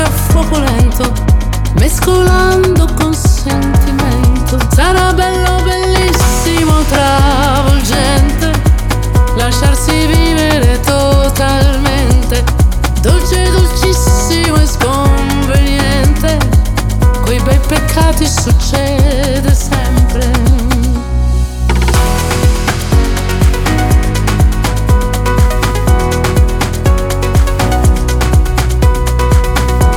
Жанр: Поп музыка / Танцевальные